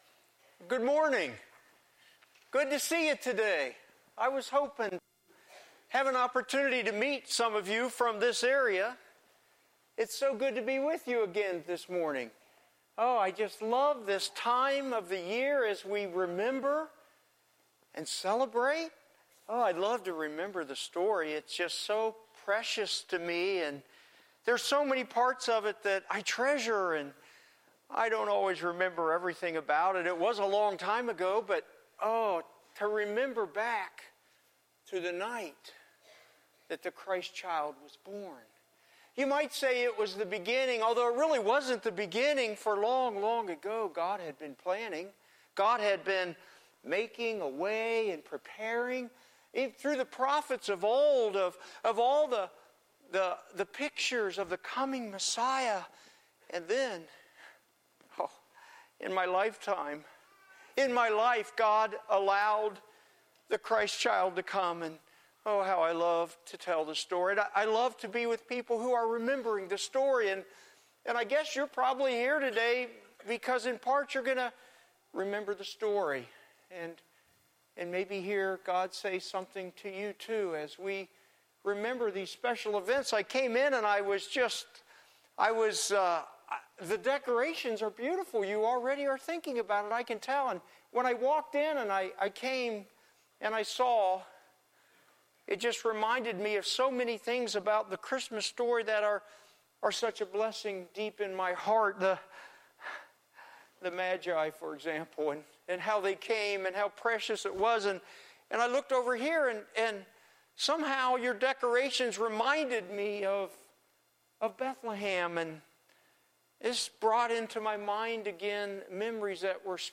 10:30AM Sunday Advent Season